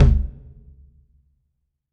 9LOWTOM1.wav